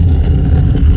stndoor.wav